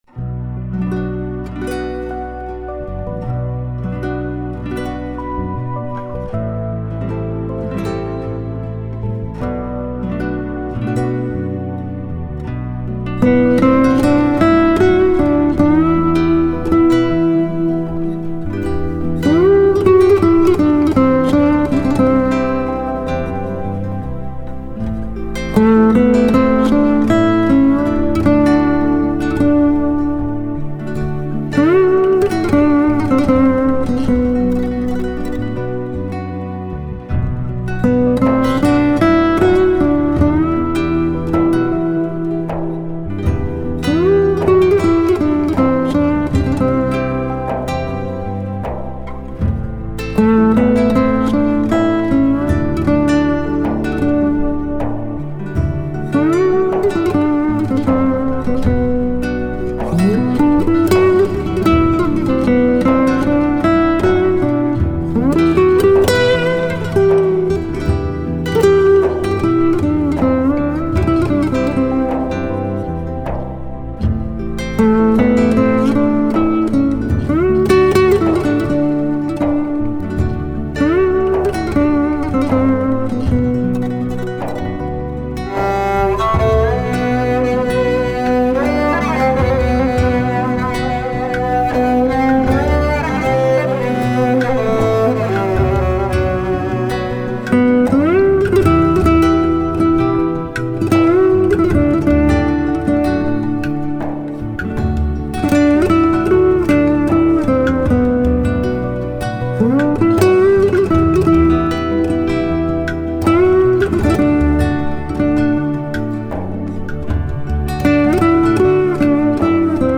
مقطوعة موسيقيّة تركيّة أخرى ، للمبدعين